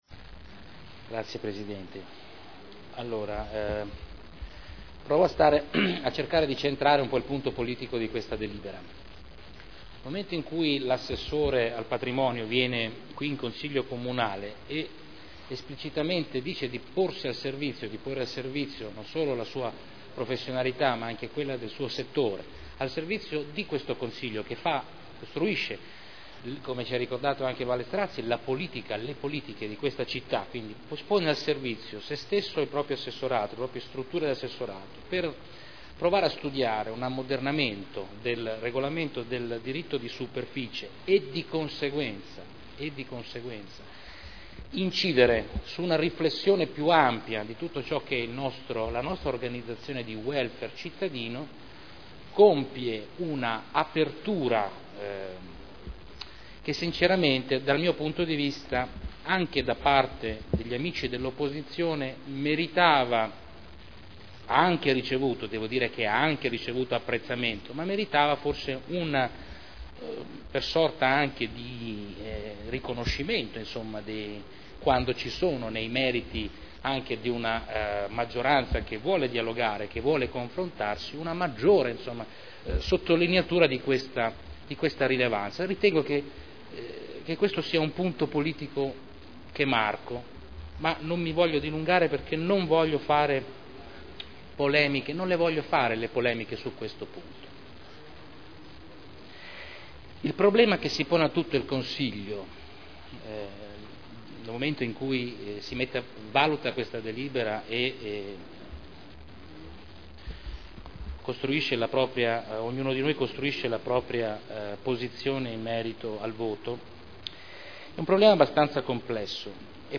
Salvatore Cotrino — Sito Audio Consiglio Comunale
Seduta del 13/12/2010 Deliberazione: Approvazione degli indirizzi per la concessione in diritto di superficie di aree comunali Dibattito